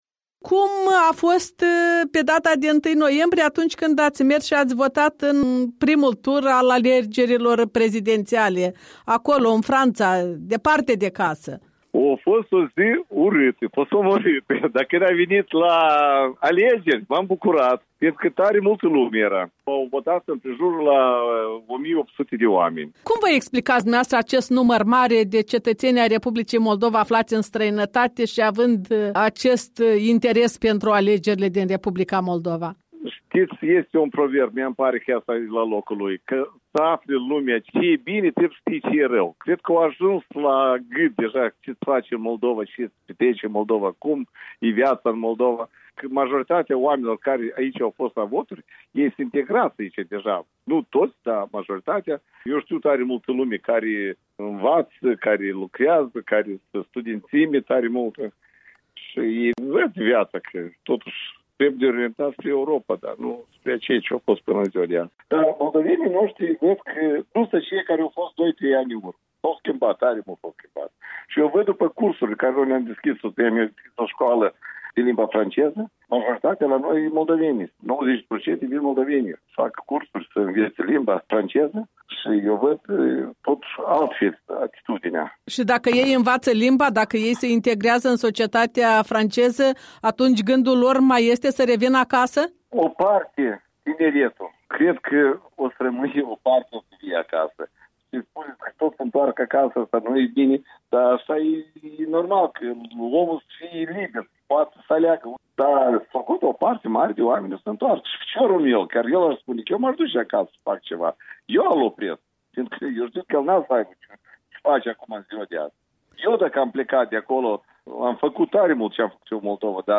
Interviu cu un moldovean stabilit la Nisa, Franța.